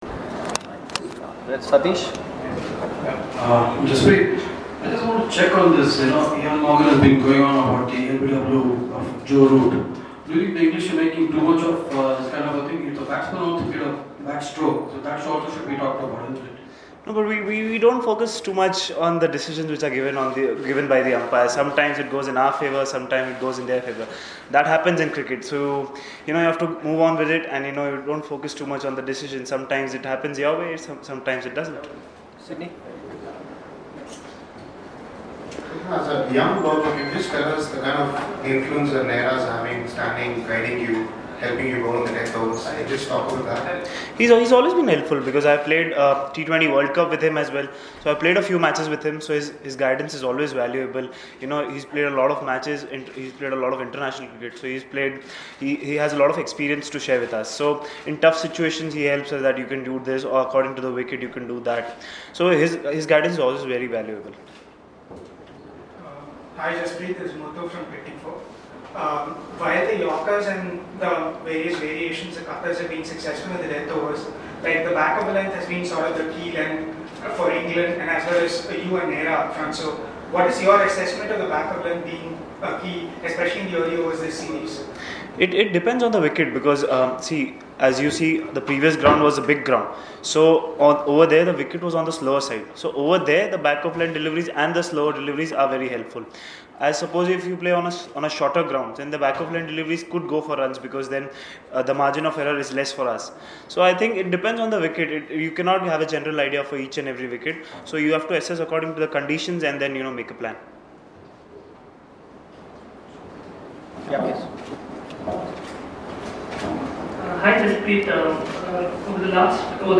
LISTEN: Jasprit Bumrah speaks on the eve of Cuttack ODI